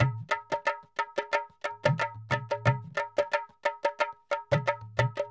2021 Total Gabra Dholki Loops